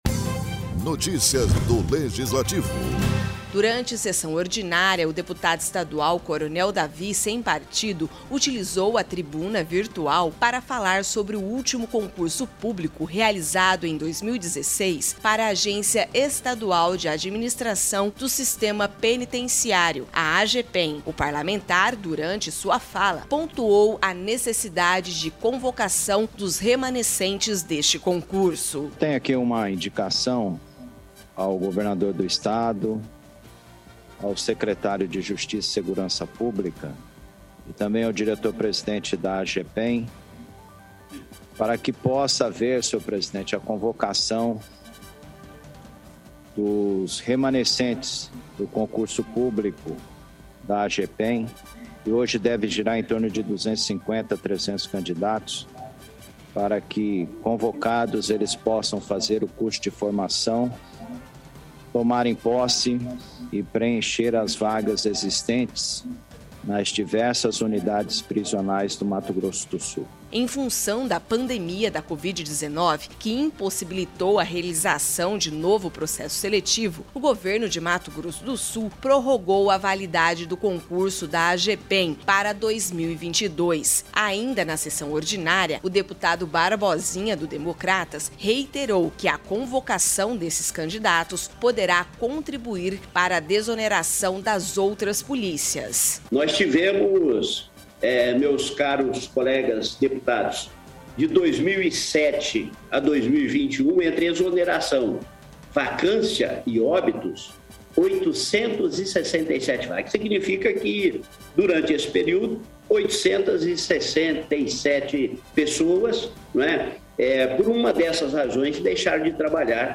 Durante sessão ordinária desta quinta-feira (11), o deputado estadual Coronel David (sem partido), utilizou a tribuna virtual, para falar sobre o último concurso público, realizado em 2016, para a Agência Estadual de Administração do Sistema Penitenciário (Agepen).